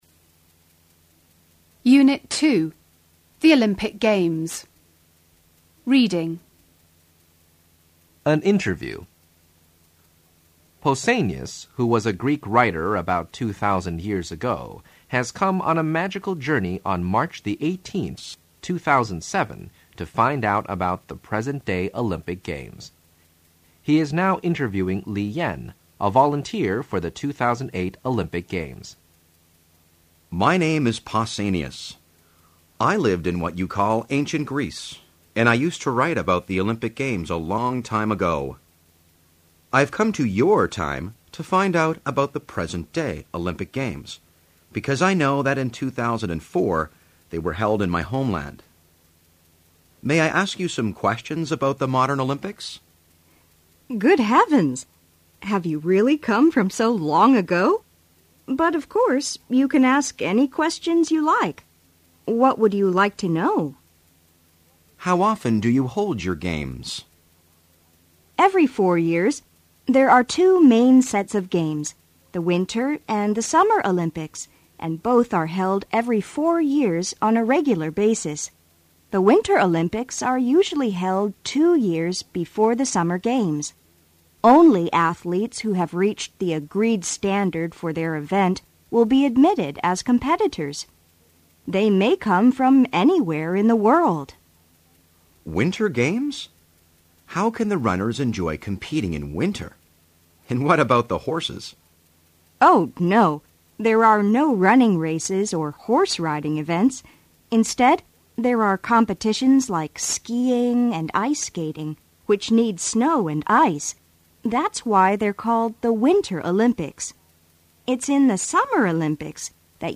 Unit 2 An Interview